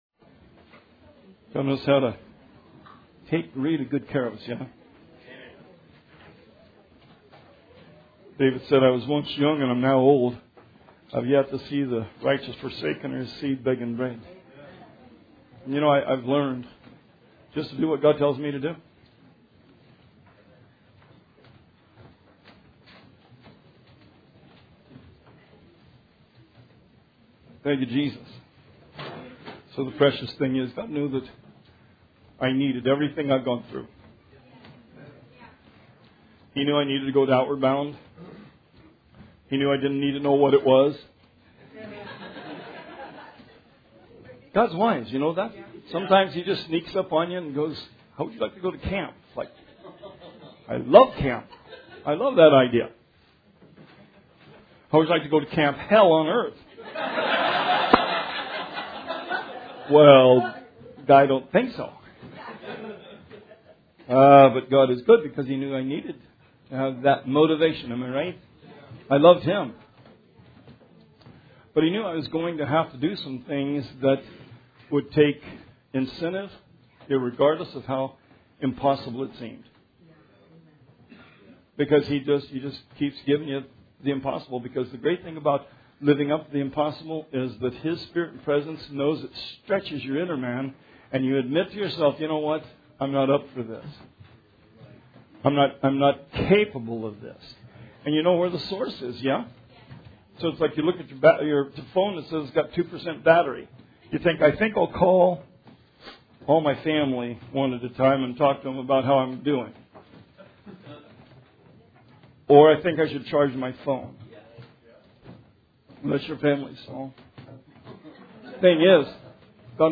Sermon 9/8/19